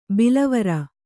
♪ bilavara